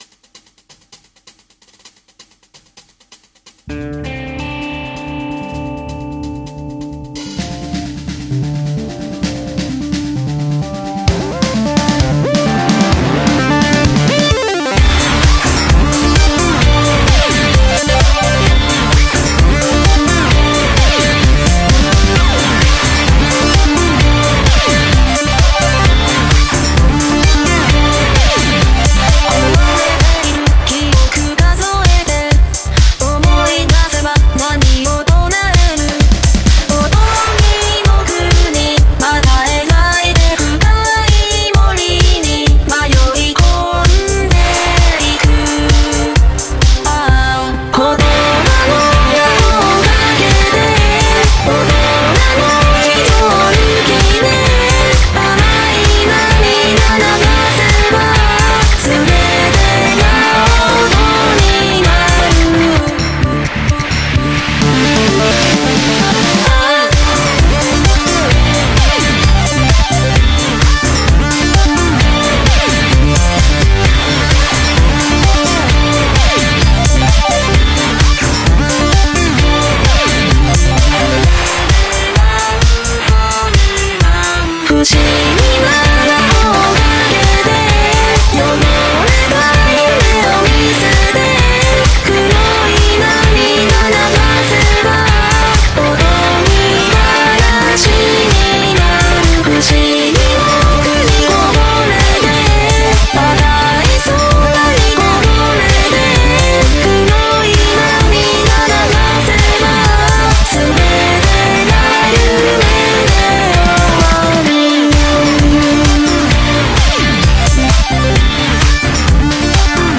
BPM130-130